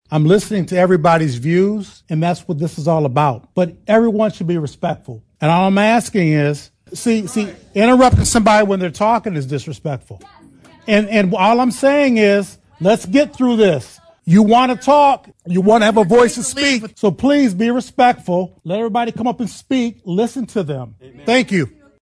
Jackson, Mich. (WKHM) — The Jackson County Board of Commissioners meeting on Tuesday night featured a packed room and multiple hours of public comment regarding the Sheriff’s Office’s (JCSO) 287(g) Agreement with ICE.
Tensions flared multiple times in the room, with Board Chair Steve Shotwell threatening to have the hall cleared, and one audience member being removed from the meeting at a later time.
Jackson County Undersheriff Tony Stewart spoke to the room at one point about respecting the time people had at public comment, regardless of viewpoint.